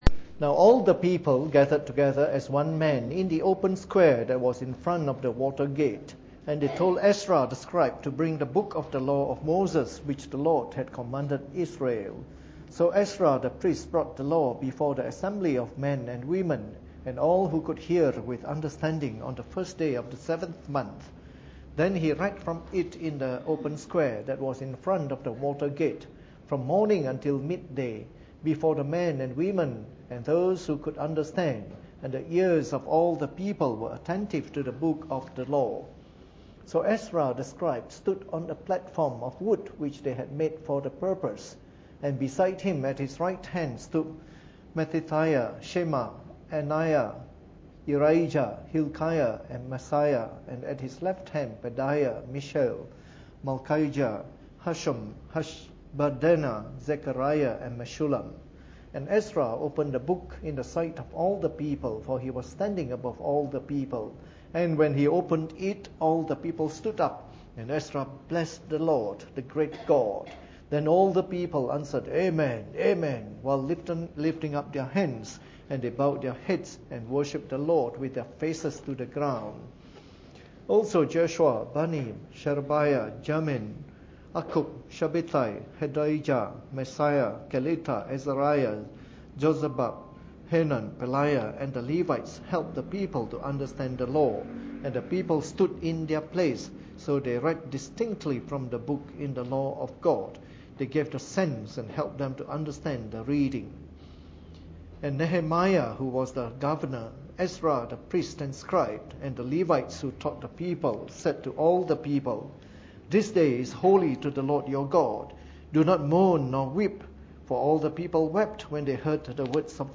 Preached on the 18th of June 2014 during the Bible Study, from our series of talks on the Book of Nehemiah.